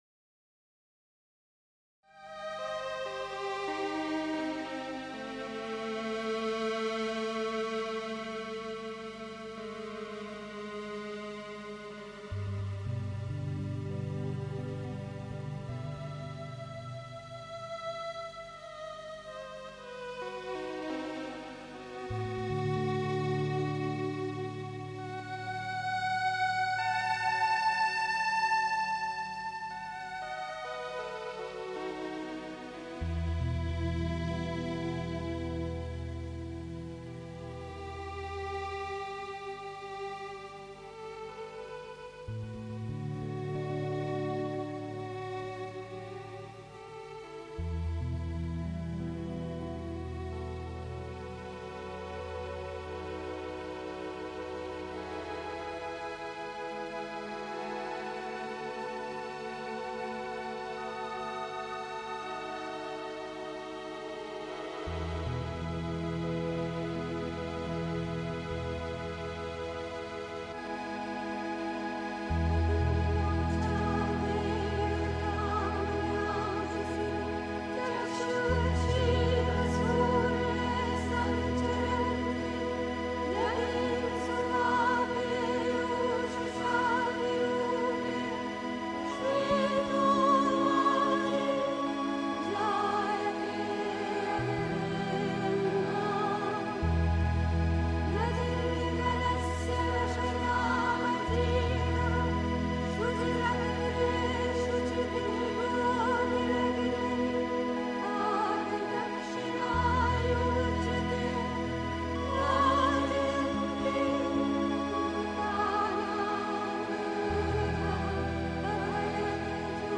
Pondicherry. 2. Der Menschheit dienen (Die Mutter, The Sunlit Path) 3. Zwölf Minuten Stille.